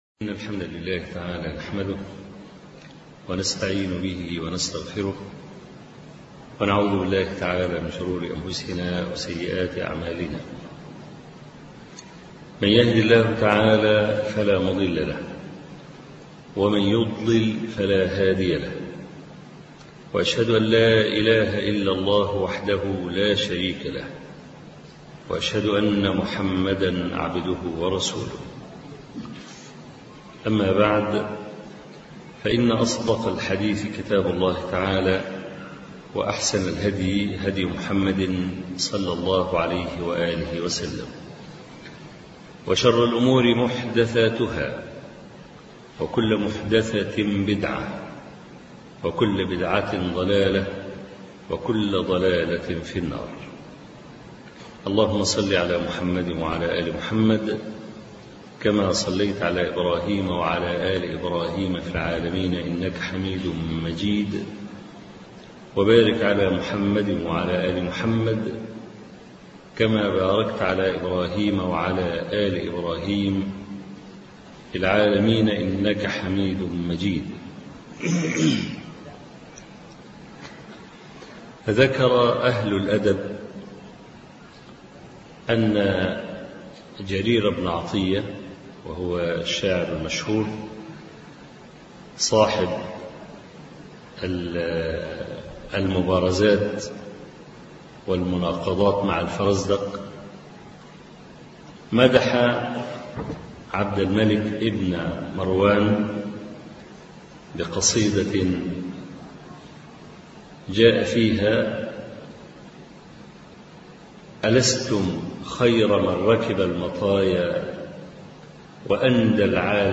خطب ومحاضرات